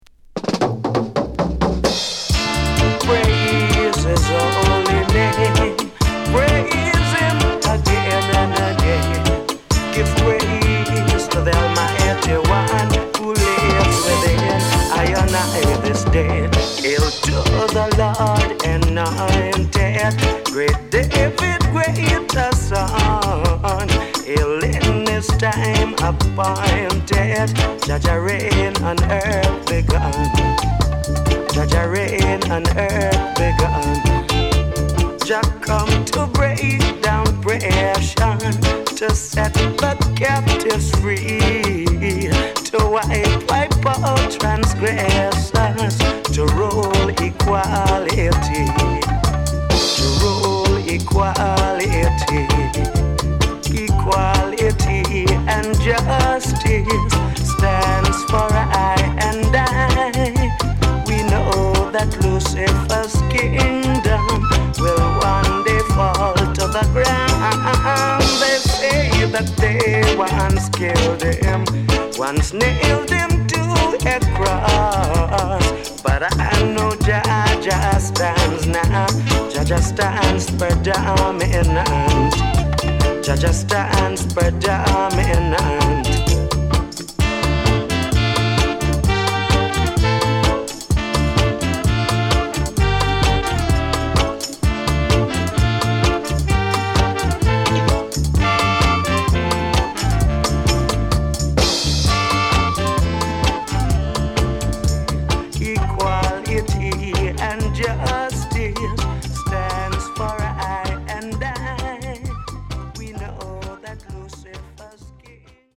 HOME > LP [VINTAGE]  >  KILLER & DEEP